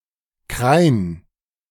Carniola (Slovene: Kranjska [ˈkɾàːnska];[1] German: Krain [kʁaɪn]